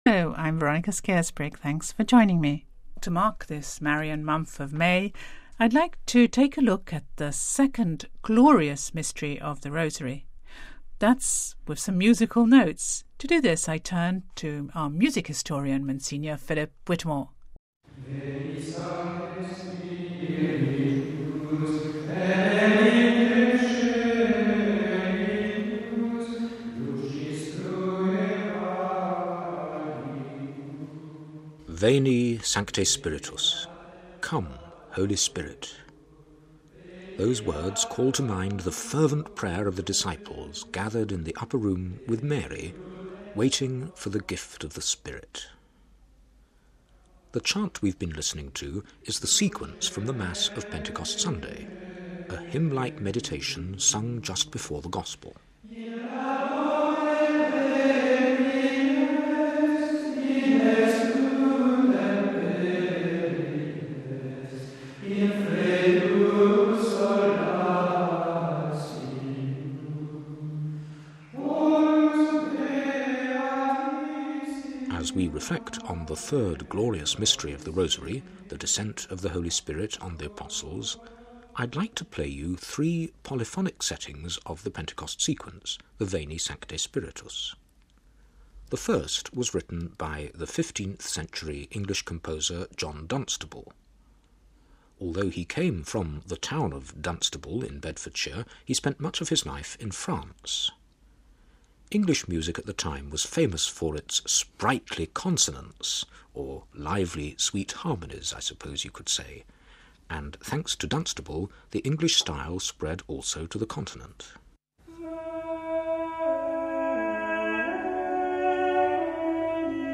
Home Archivio 2008-05-09 14:46:04 DESCENT OF THE HOLY SPIRIT Marking Pentecost with musical notes drawn from Dunstable, de Victoria and Maxwell Davies.